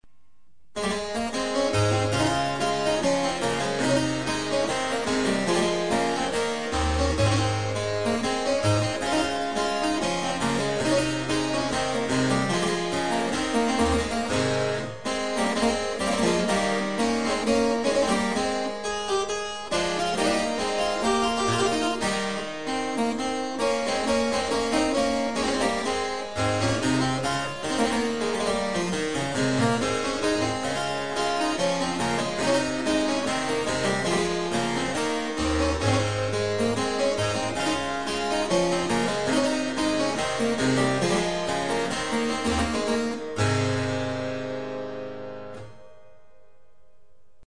CLAVECIN FLAMAND
Le jeu de luth est comme l'original en deux parties, divisé entre do# et ré.
La caisse est en peuplier, le clavier est en tilleul avec les marches en ébène.
Il a comme l'original deux jeux de 8 pieds.
Vous pouvez écouter ce clavecin dans un extrait de La Champenoise de Dornel en cliquant ici.